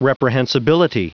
Prononciation du mot reprehensibility en anglais (fichier audio)
reprehensibility.wav